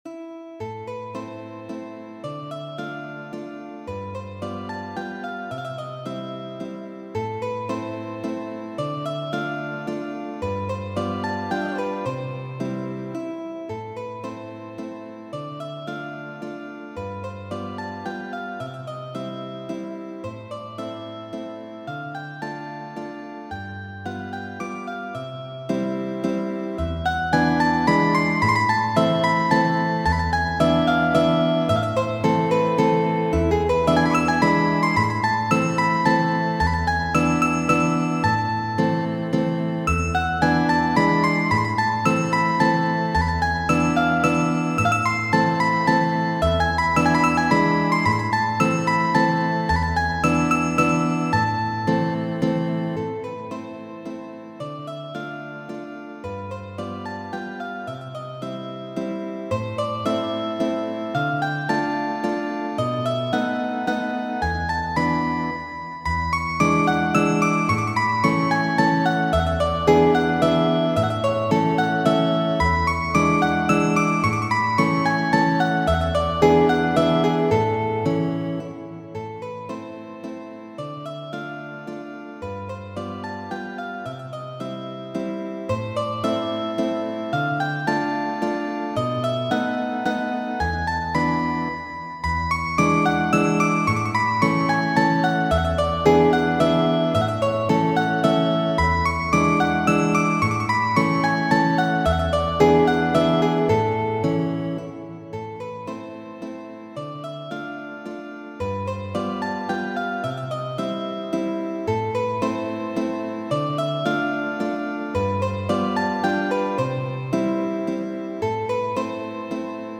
Muziko
Valso lenta de Federiko Ŝopin'.